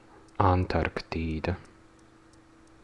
Ääntäminen
Ääntäminen US UK : IPA : /ˌænˈtɑːk.tɪk.ə/ US : IPA : /ænˈtɑɹktɪkə/ Haettu sana löytyi näillä lähdekielillä: englanti Käännös Ääninäyte Erisnimet 1.